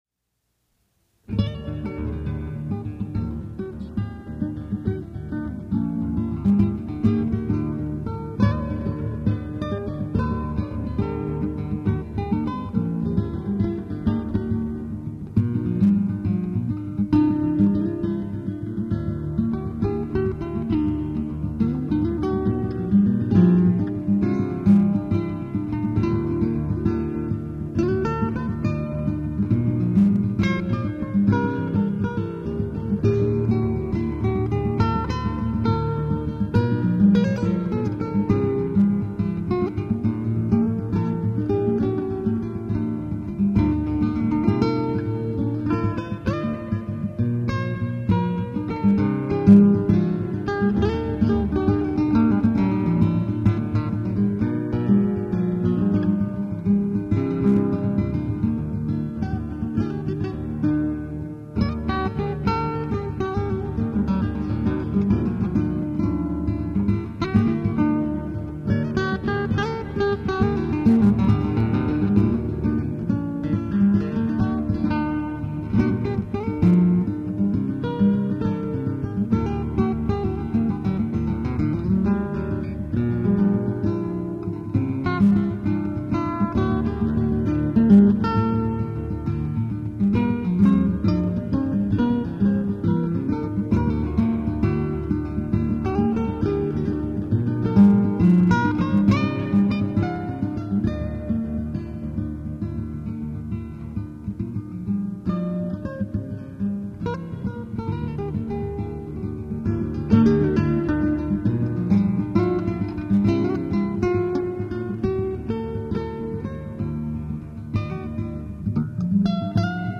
Instrumentals: